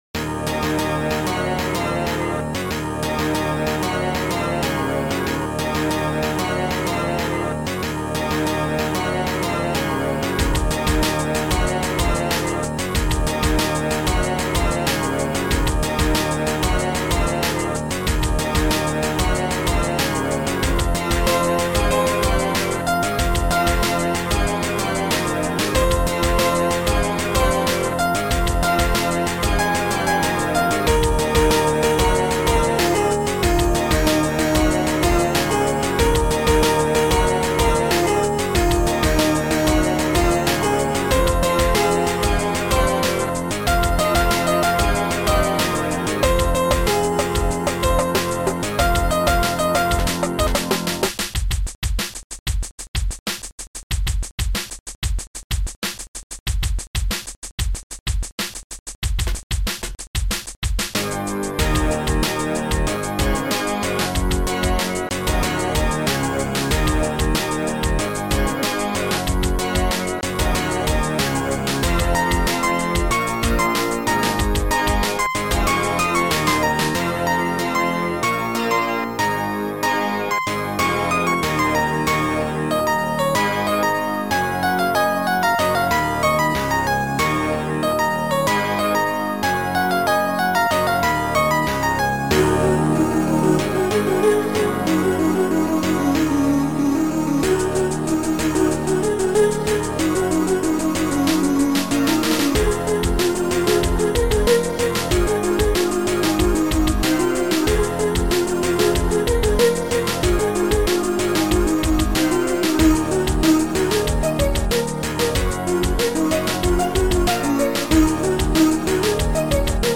Sound Format: Noisetracker/Protracker